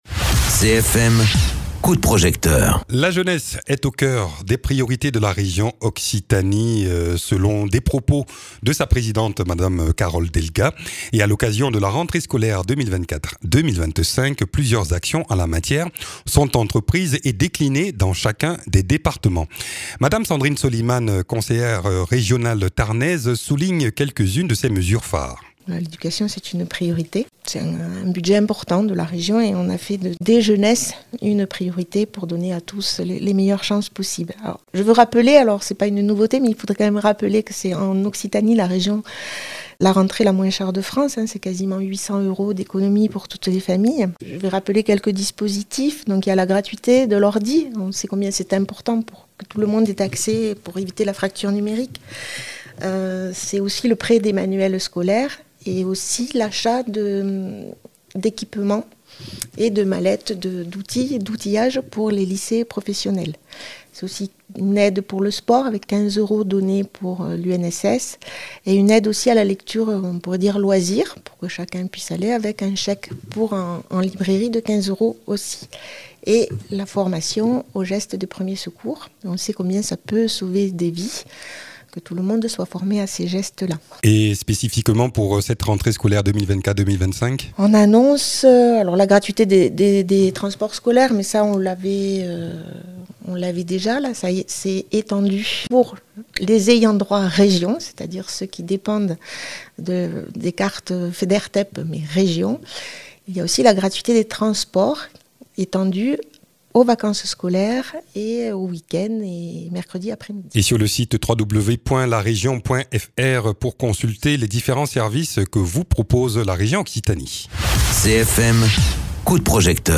Interviews
Invité(s) : Sandrine Soliman, élue de la région Occitanie.